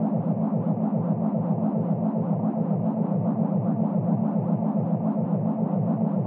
sci-fi_forcefield_hum_loop_02.wav